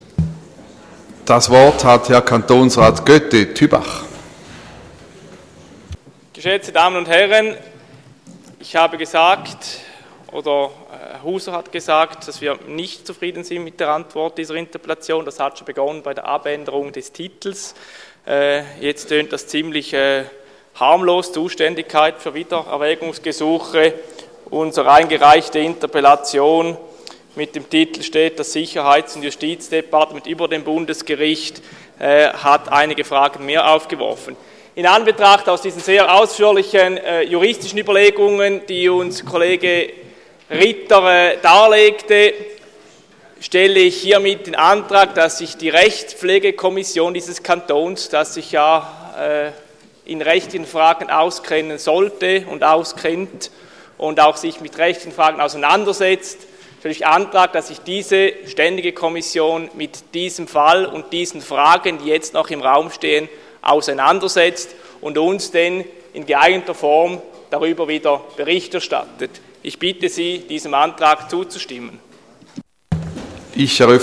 Session des Kantonsrates vom 24. bis 26. November 2014